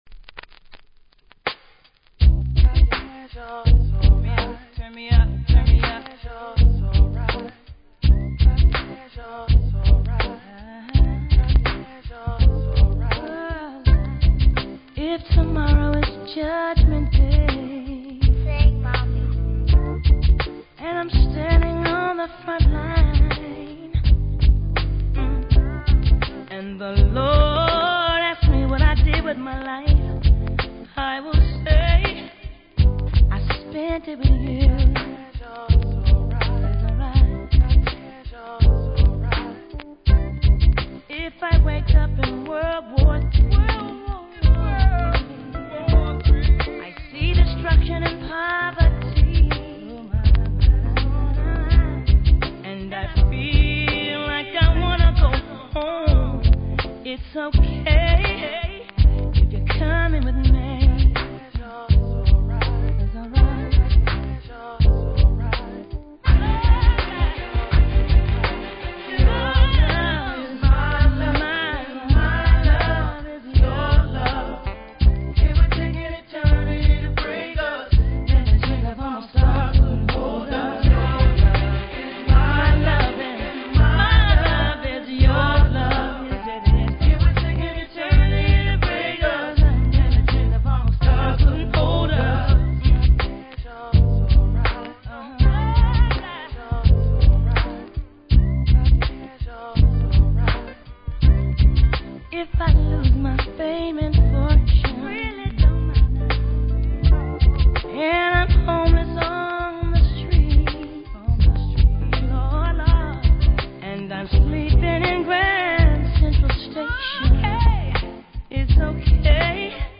ノイズありますので試聴で確認下さい。